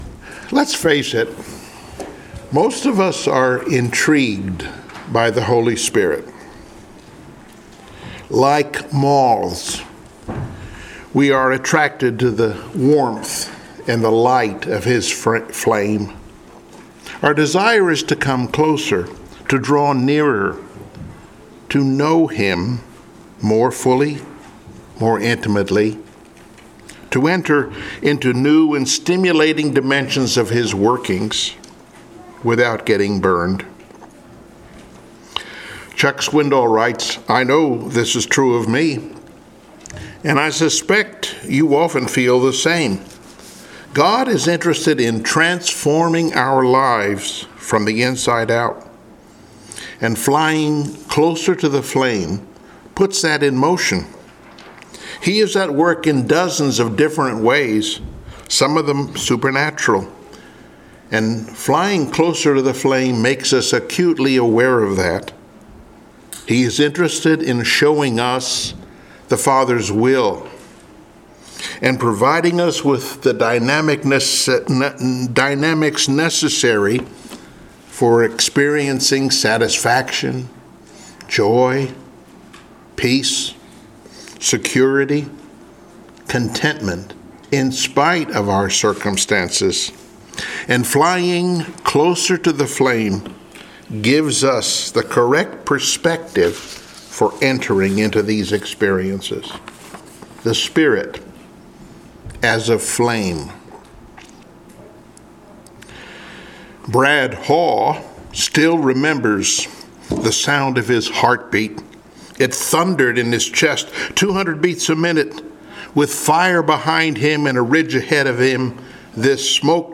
Service Type: Testimonial